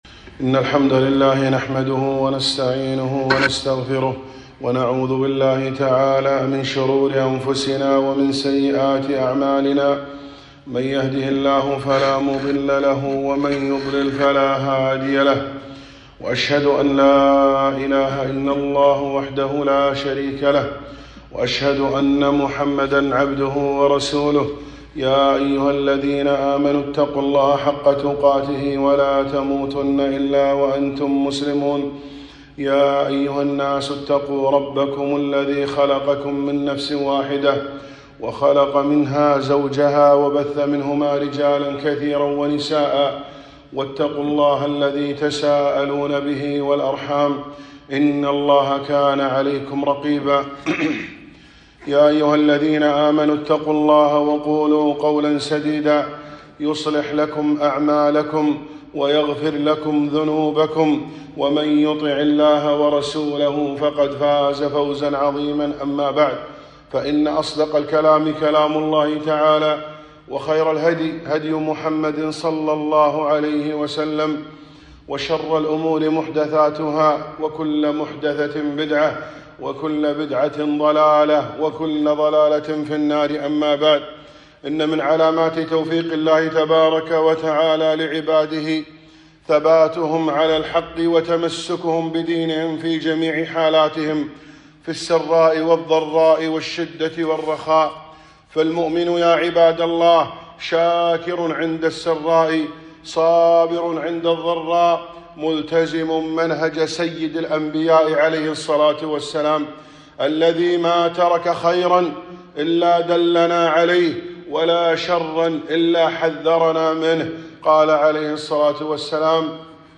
خطبة - اللهم إنا نجعلك في نحورهم